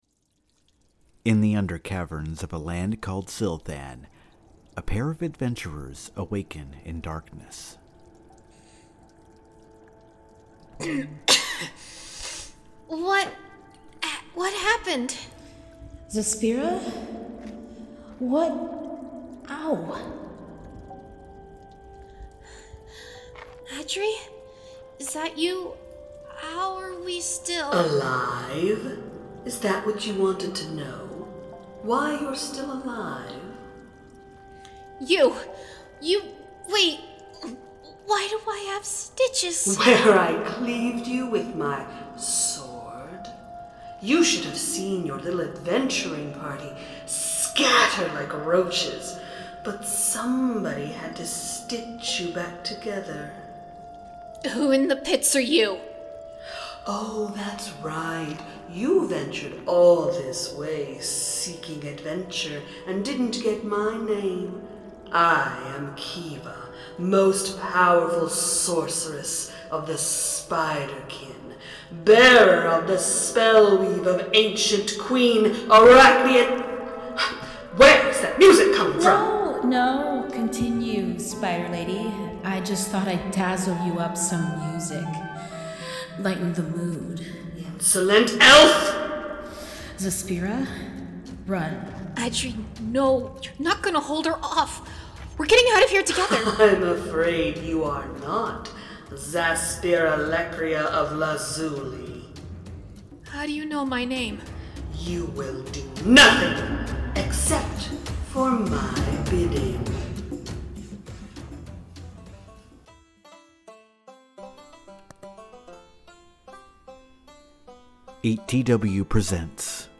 best audio drama